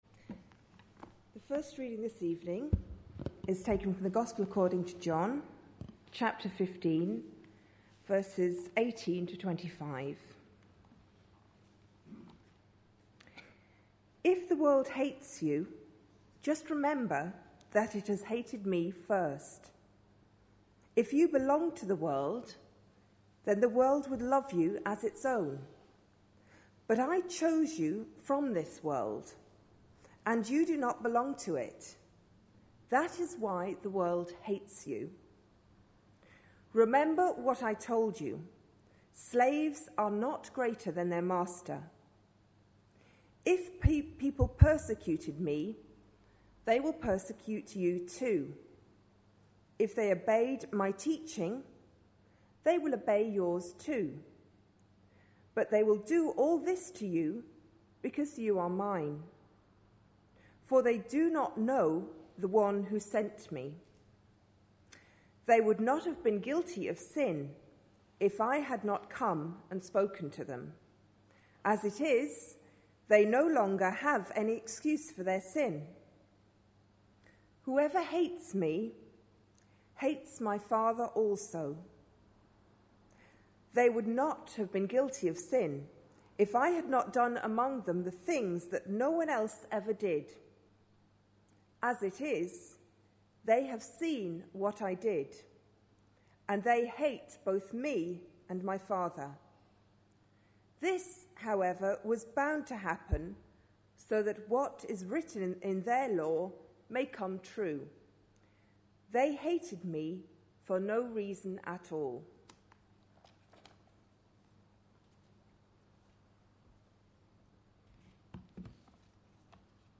A sermon preached on 23rd May, 2010, as part of our Big Issues series.